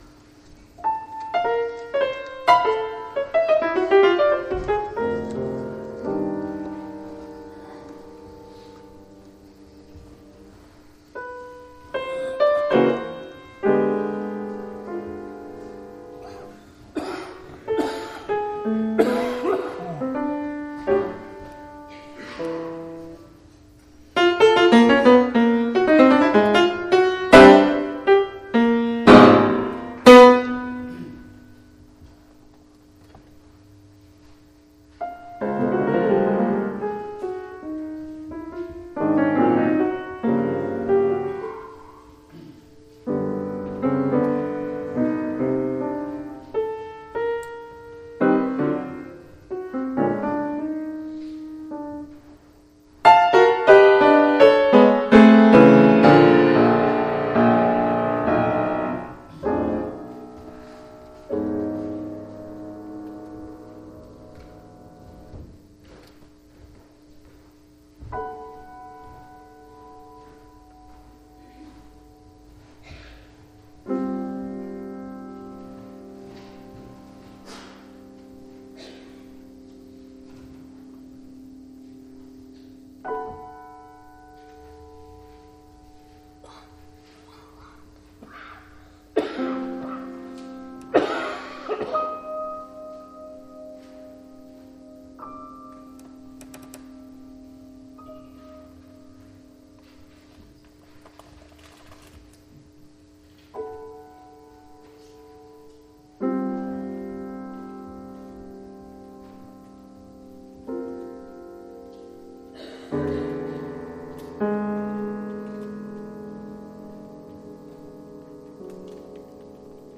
OFFERTORY -  Sechs kleine Klavierstucke (Six Little Piano Peces) Op.19, 1911
The atonality in these miniatures predates Schoenberg's later